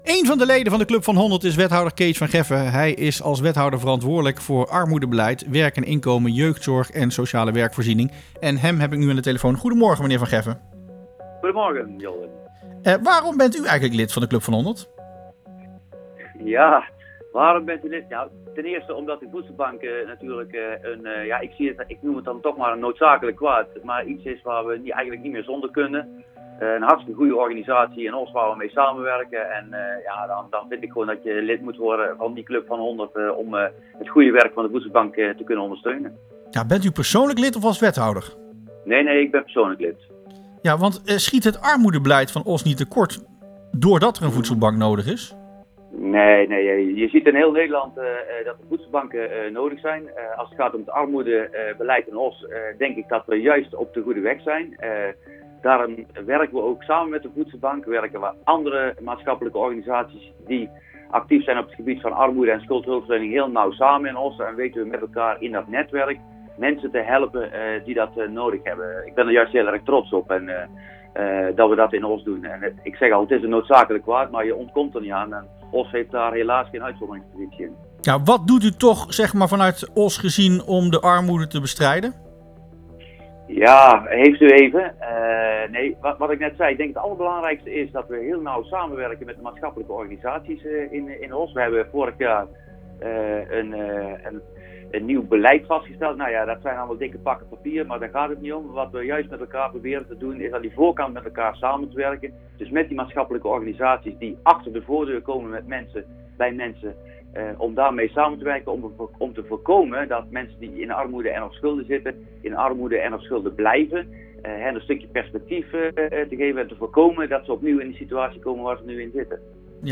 Wethouder armoedebeleid Kees van Geffen